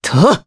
Pavel-Vox_Attack3_jp_b.wav